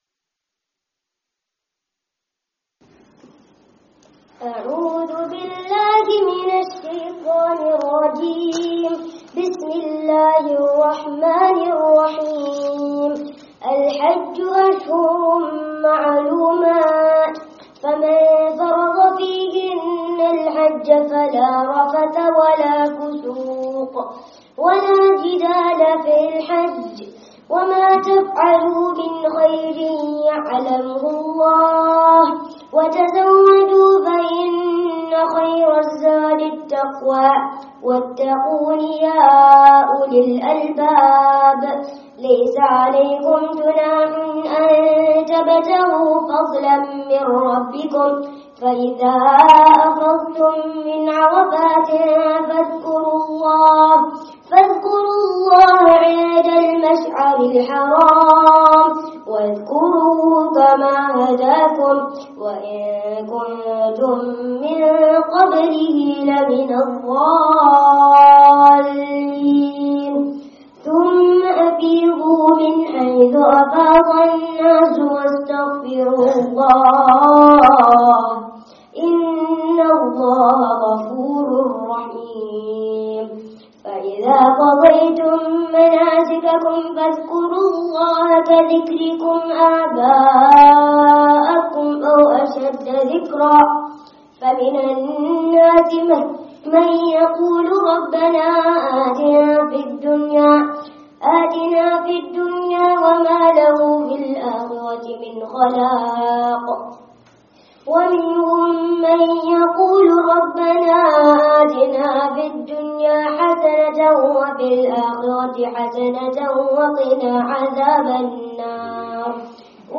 Hifz-ul-Qur'ān - A Great Bounty [Completion of the Qur'an] (Masjid Aishah, Cork Street, Leicester 04/08/19)